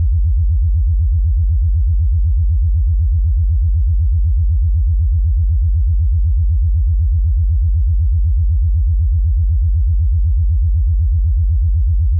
ピンクノイズでは、測定結果を積分しなければなりませんが、１オクターブバンドとなるように、FM変調した正弦波を使用すれば、レベル変動が有りませんので、積分の必要も無く、リアルタイムに、電圧を測るような感じで、音圧が測定できます。
FM変調で、周波数が動いていますので、定在波の影響が少なく、信頼度の高い測定になります。
１オクターブバンドFM音源ダウンロード
使用する音源　44.1kHz16ビット各12秒　　ダウンロードしてお使いください。
１．20Hz〜40Hz 31WAV